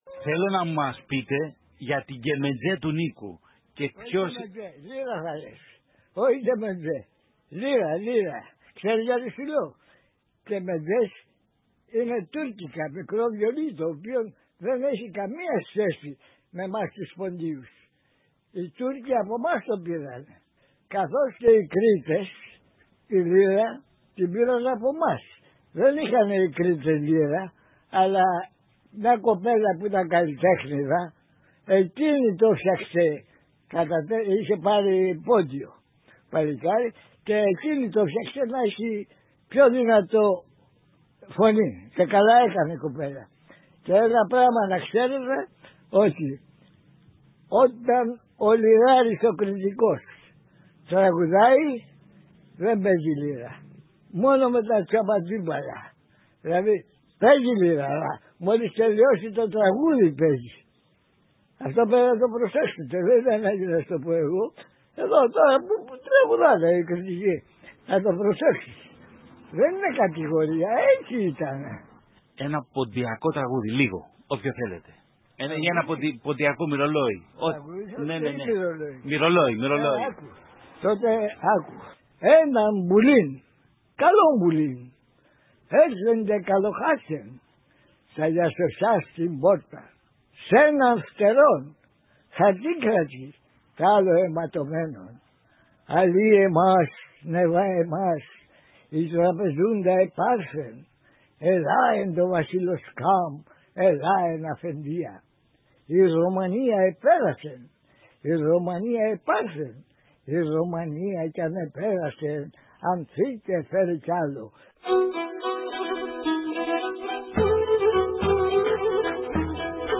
Απαγγέλει στίχους από το «’Πάρθεν η Ρωμανία» και τραγουδάει το «Αητέντς επαραπέτανεν» που του άρεσε να λέει στο συναπάντημα νεολαίας που γίνεται στην Παναγία Σουμελά.